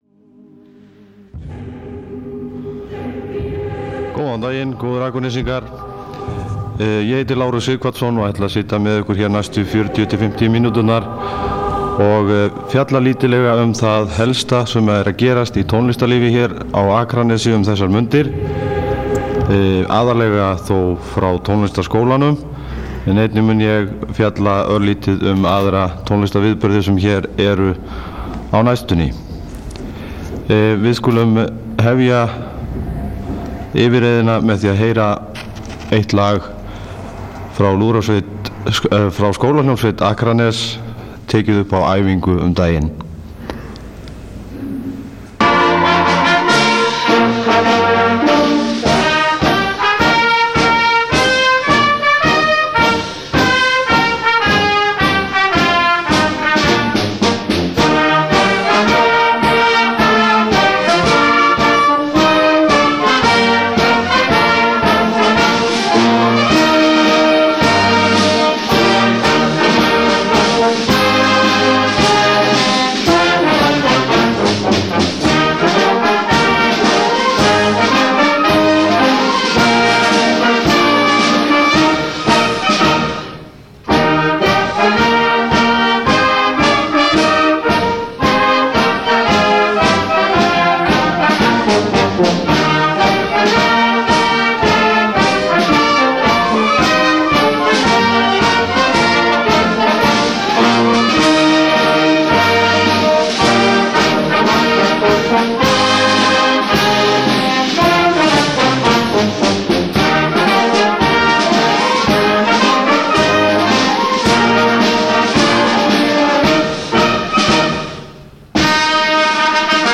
Bein útsending frá tónleikum úr sal Tónlistarskólanum.